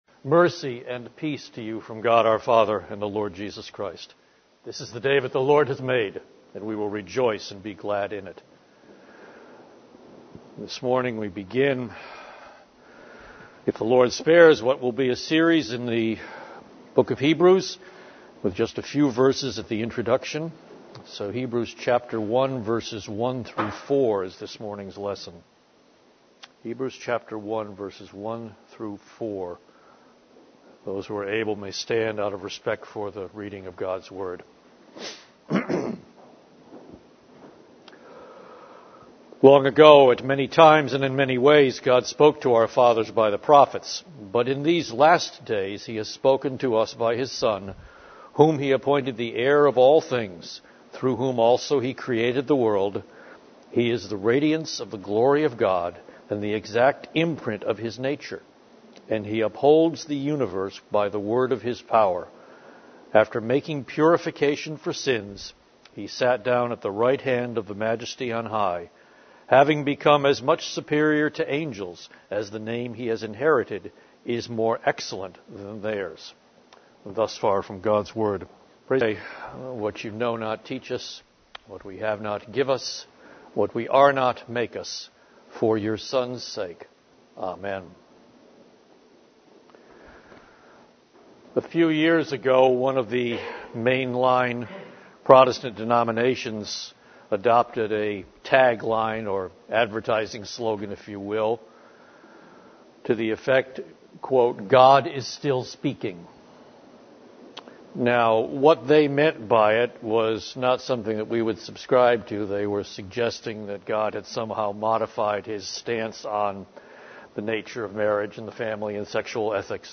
The Cornerstone Church - Sermons